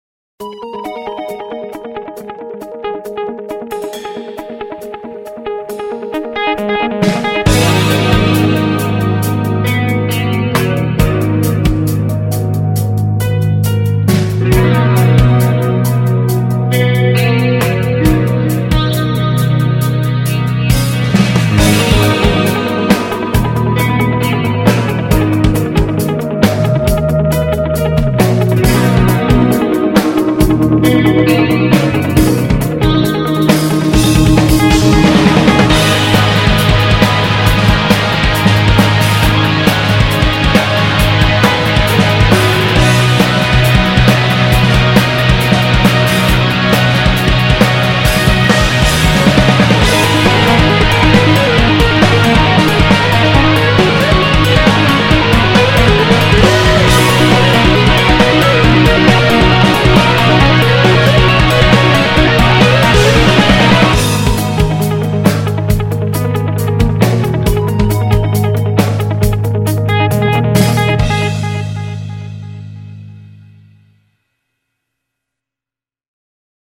주로 보컬곡만 쓰시다가 연주곡을 간만에 쓰셨군요.